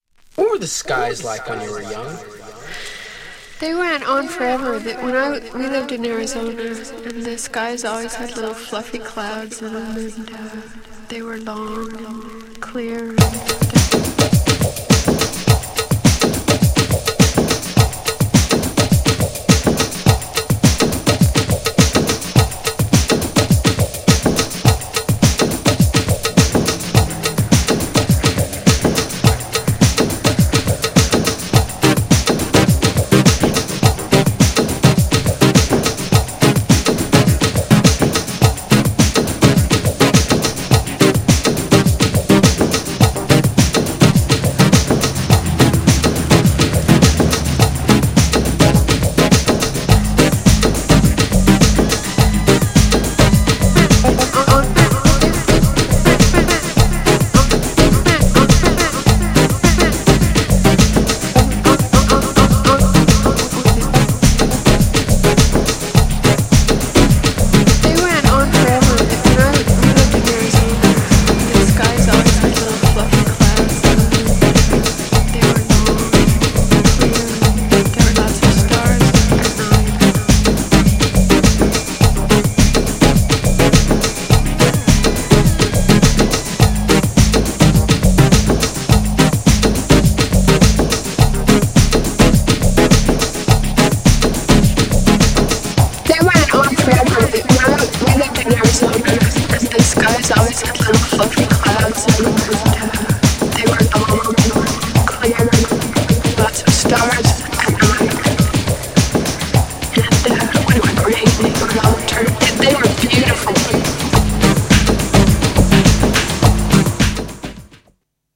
ダウンテンポ
GENRE House
BPM 101〜105BPM